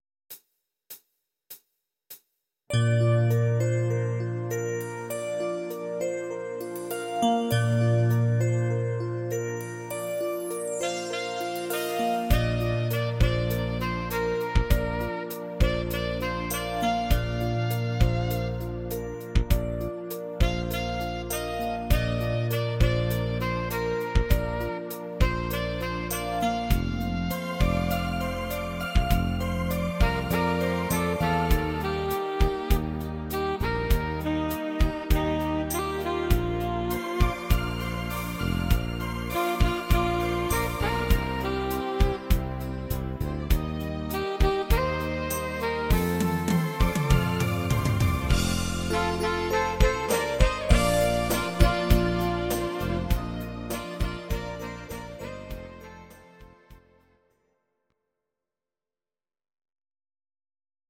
Audio Recordings based on Midi-files
German, Duets